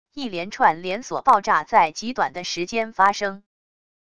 一连串连锁爆炸在极短的时间发生wav音频